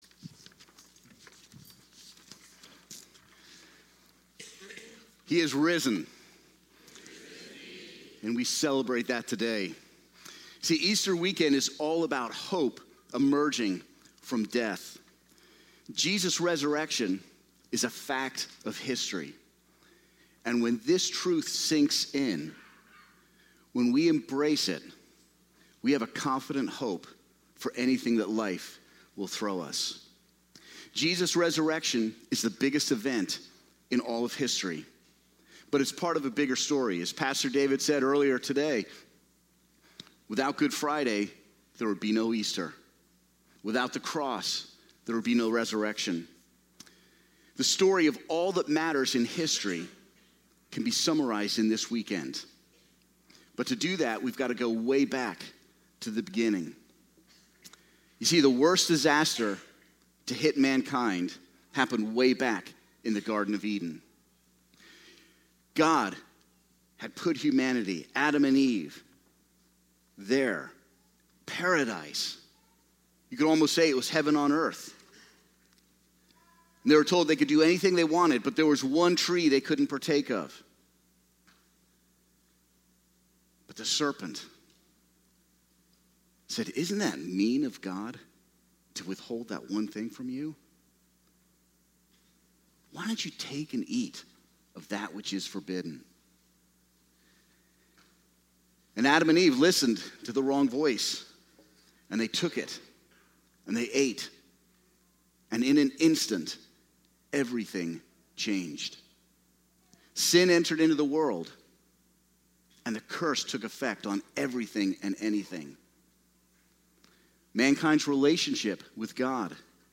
Easter Service
Sermon